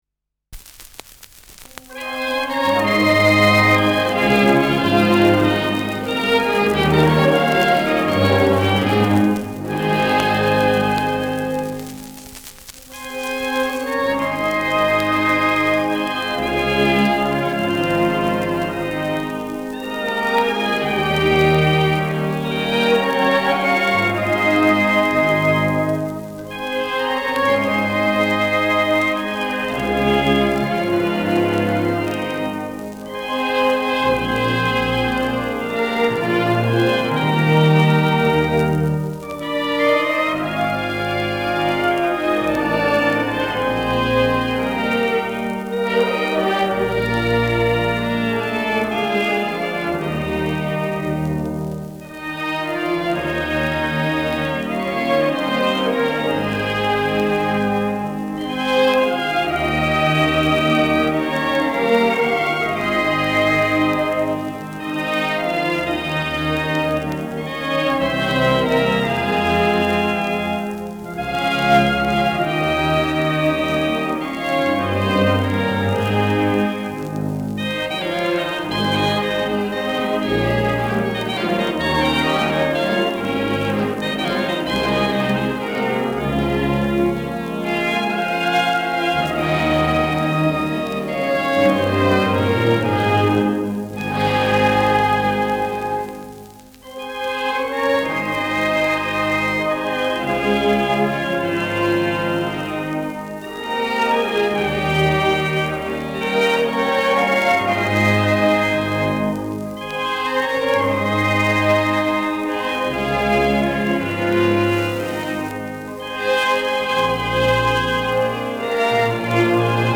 Schellackplatte
leichtes Knistern
Große Besetzung mit viel Hall, die einen „symphonischen Klang“ erzeugt.
[Berlin] (Aufnahmeort)